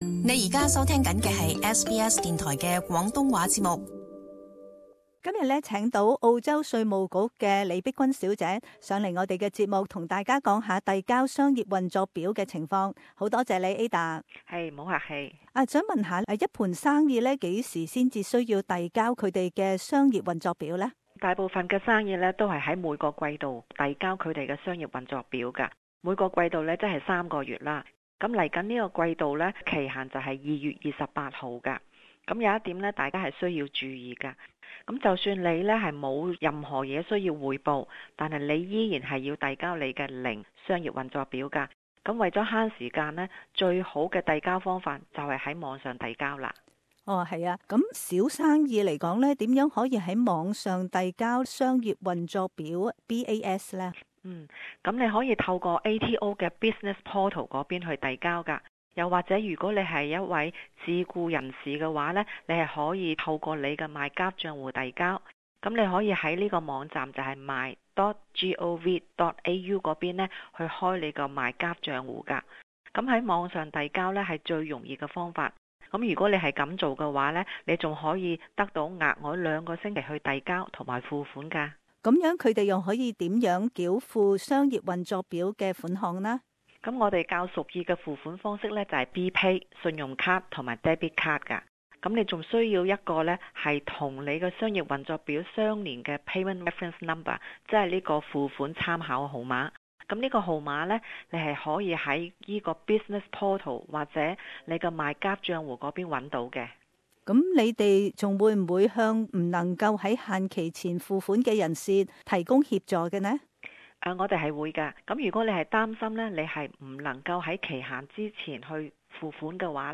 The following community information is brought to you by the Australian Texation Office. Interview